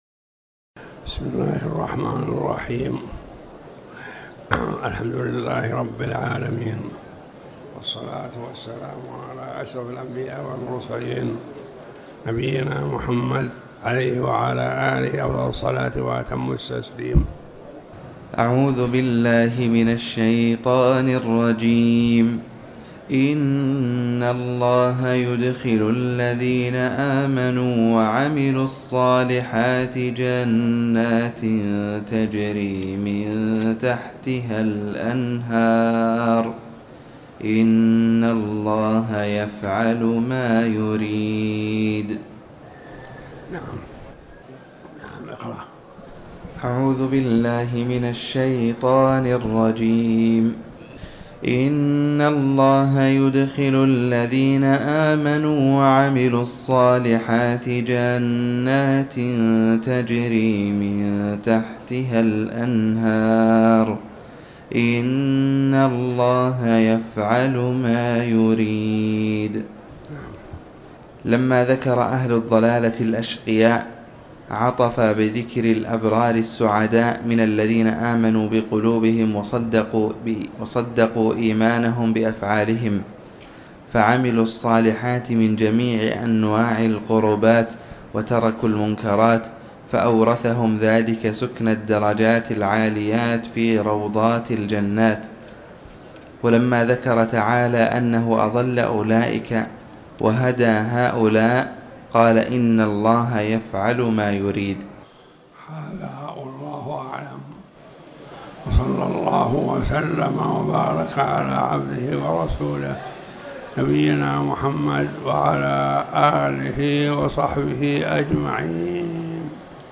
تاريخ النشر ٧ ذو القعدة ١٤٤٠ هـ المكان: المسجد الحرام الشيخ